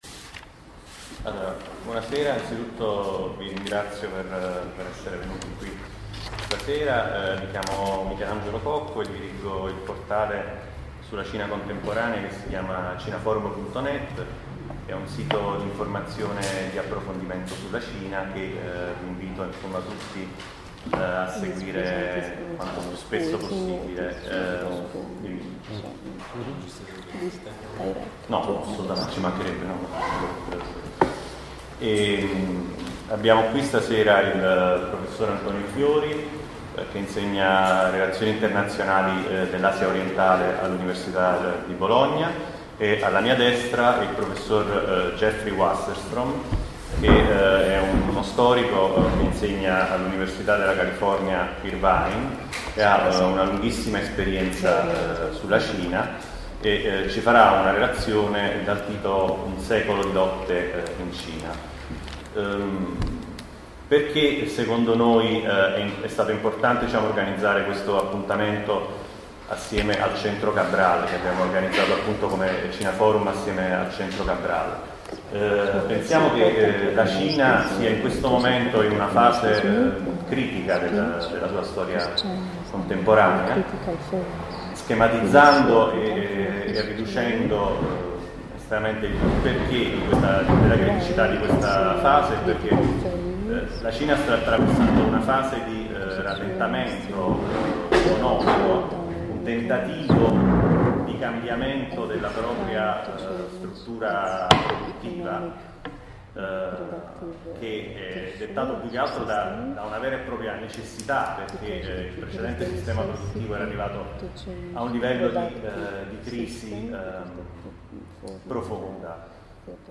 Incontro-dibattito